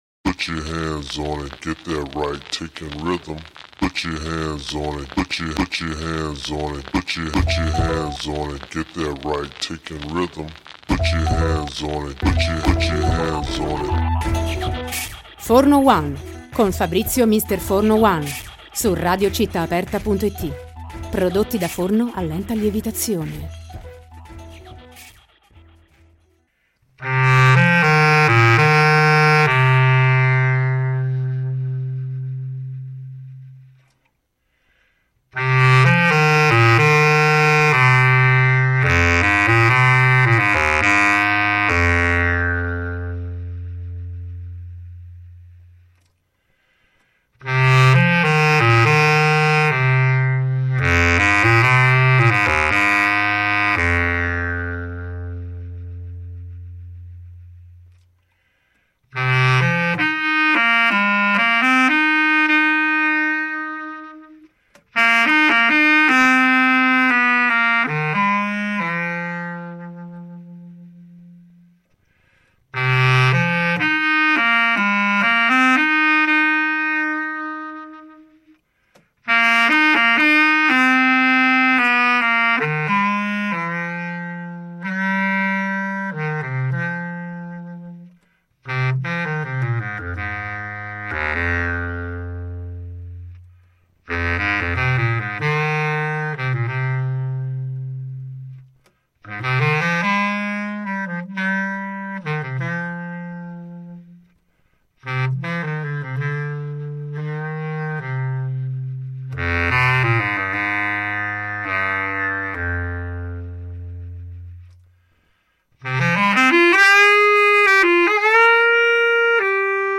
clarinettista e bassista